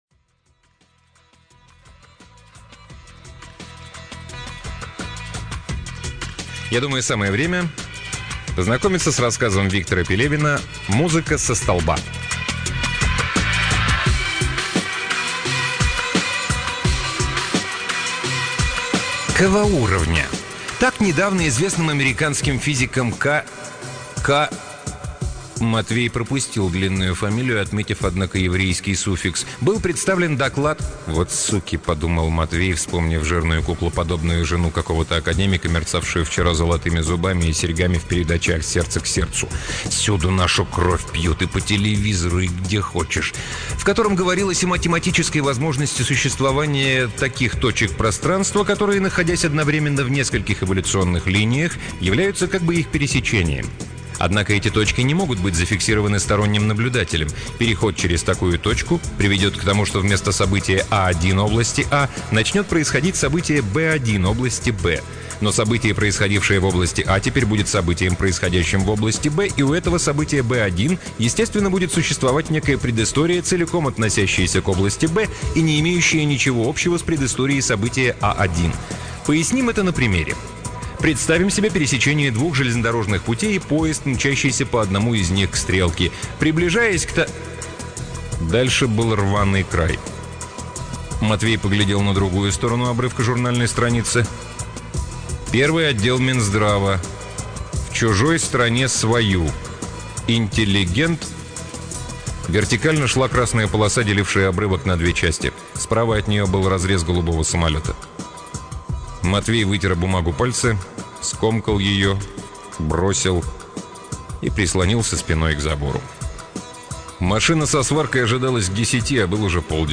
Аудиокниги автора Виктор Пелевин